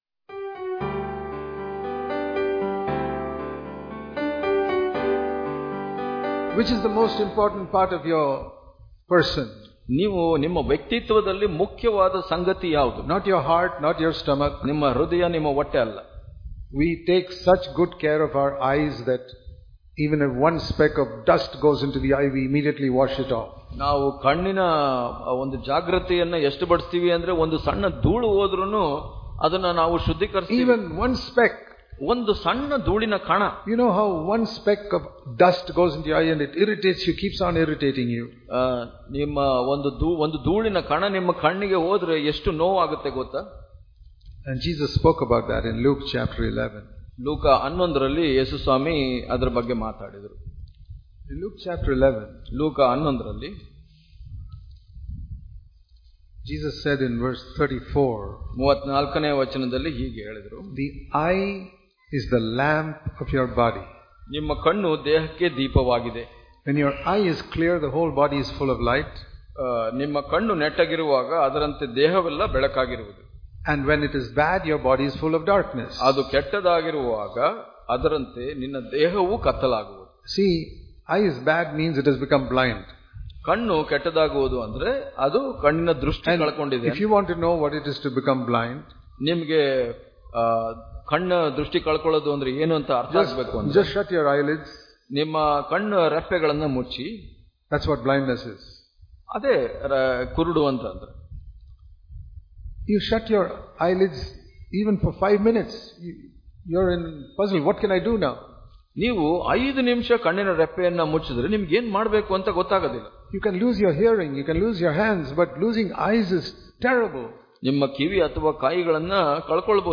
June 26 | Kannada Daily Devotion | Have A Clear Conscience Daily Devotions